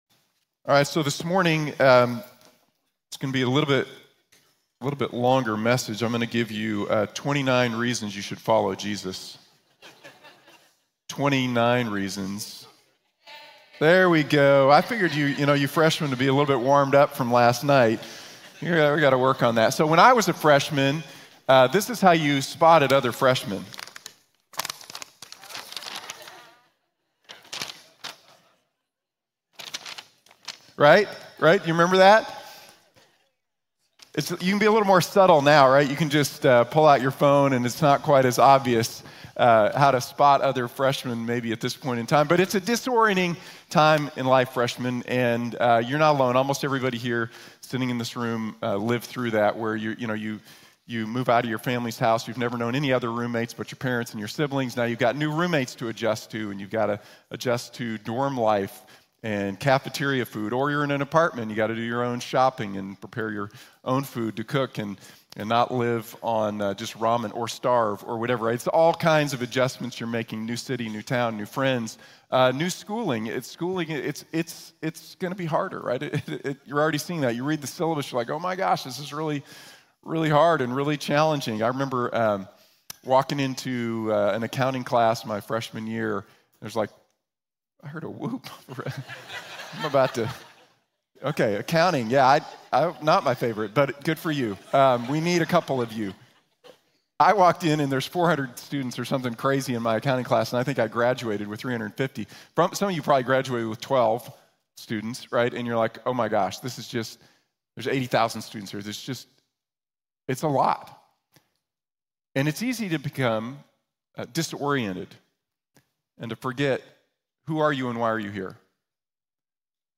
We help people find and follow Jesus | Sermon | Grace Bible Church